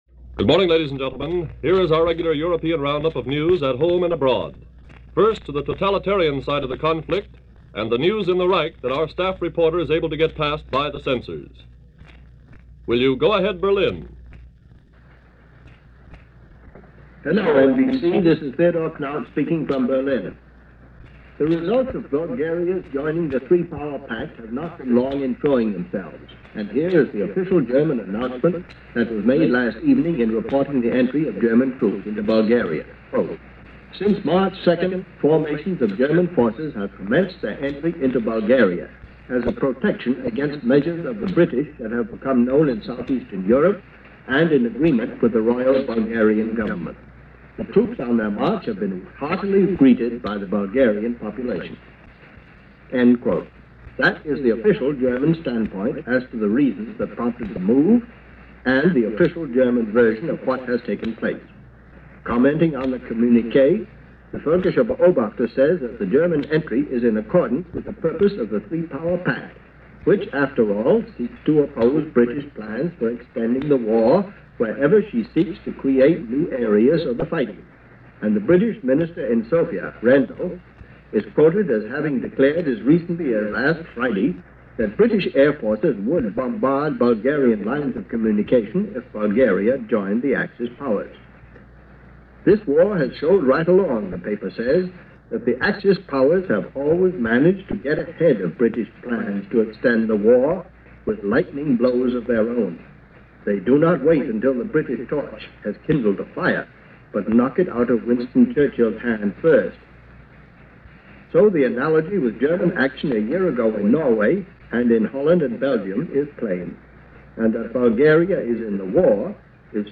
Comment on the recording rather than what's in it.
News for this day in history as presented by the NBC Blue Network.